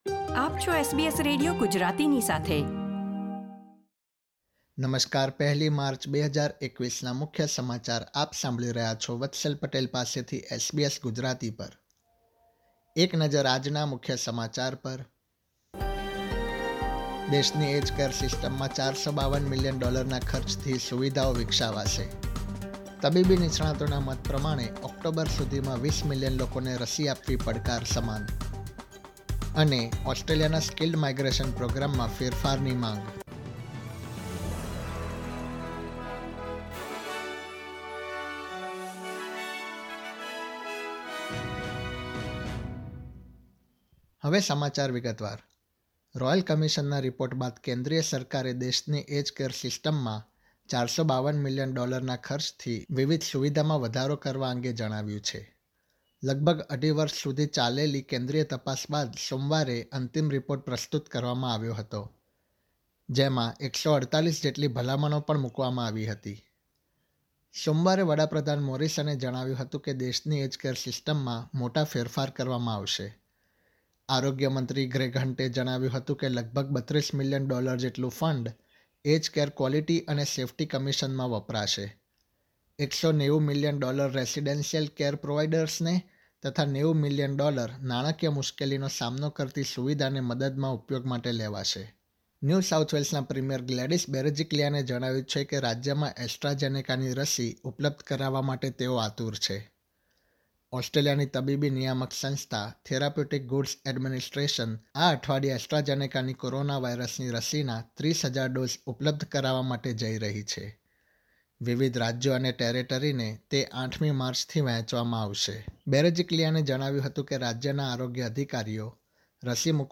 SBS Gujarati News Bulletin 1 March 2021
gujarati_0103_newsbulletin.mp3